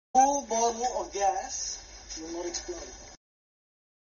Gas Explode